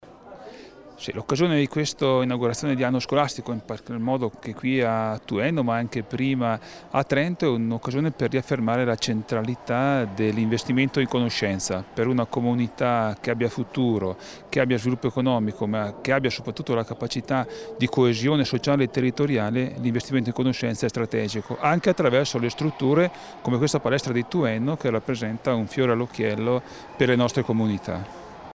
Daldoss_inaugurazione_anno_scolastico_Tuenno_.mp3